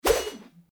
attack_blade_wp_3.mp3